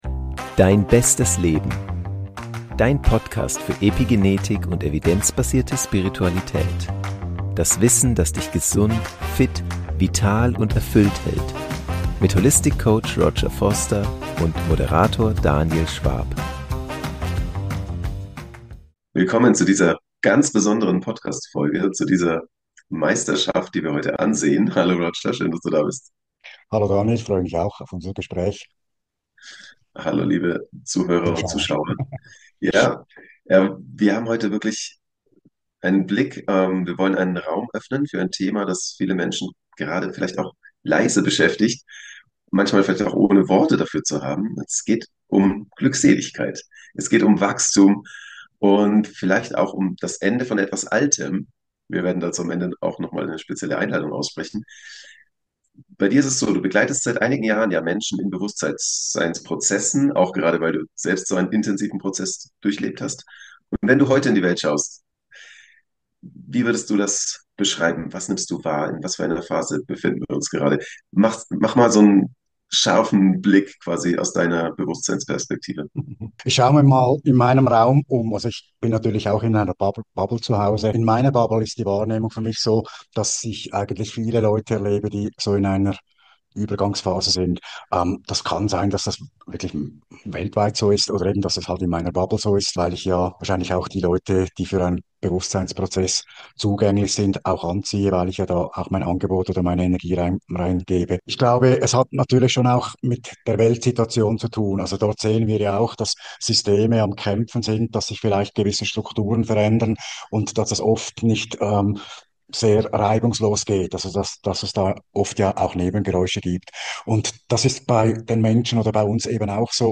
Wir streifen die 5 Prinzipien der Meisterschaft der Glückseligkeit, berühren das Bewusstseinsfeld der Gene Keys und lassen die Weisheit von Buddha, Jesus und moderner Bewusstseinsarbeit mitschwingen ... ruhig, klar und ohne Druck.